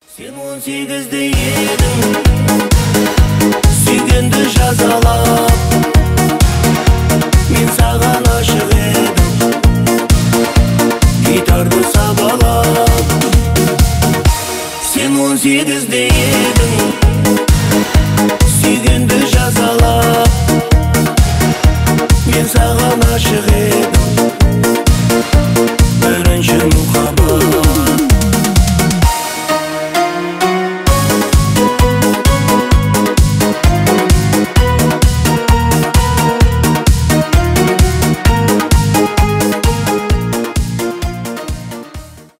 танцевальные , поп